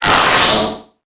Audio / SE / Cries / MORGREM.ogg